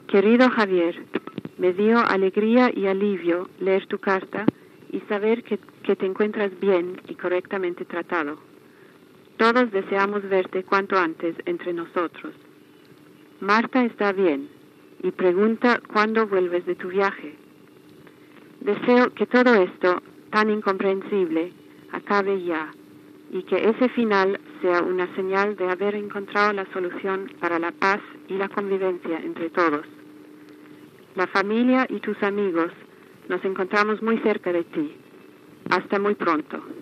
Informatiu